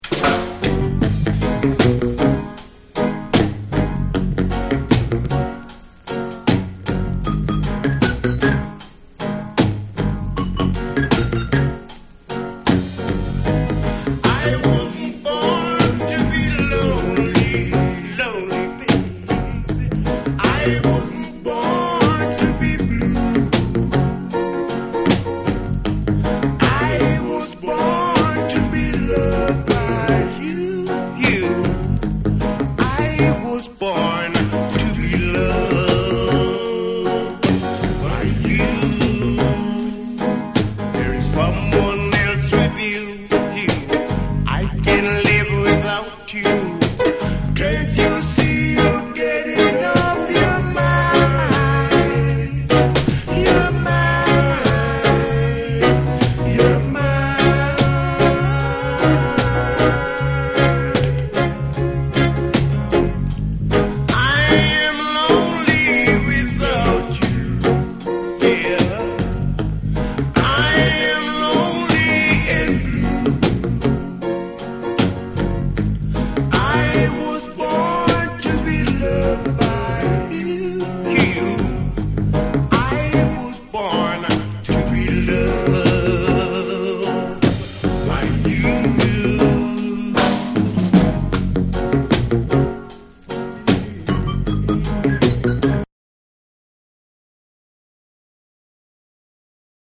レゲエ・コーラス・グループ
REGGAE & DUB